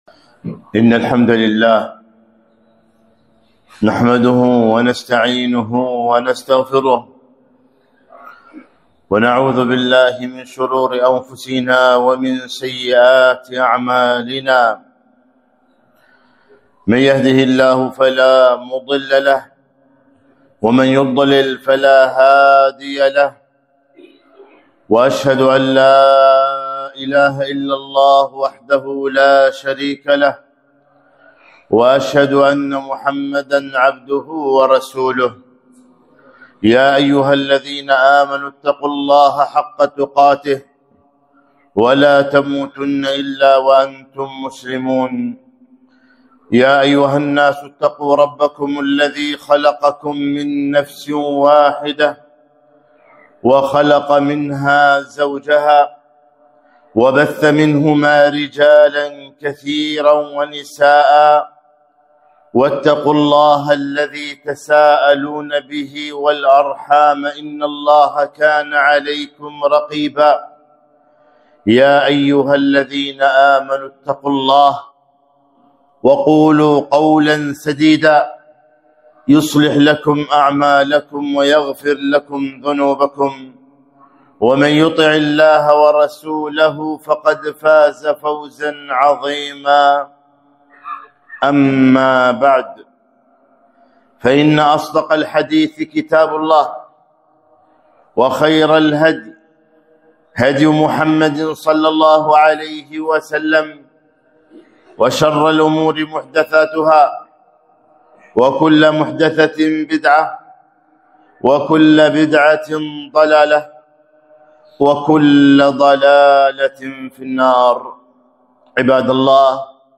خطبة - نعمة الهداية للإسلام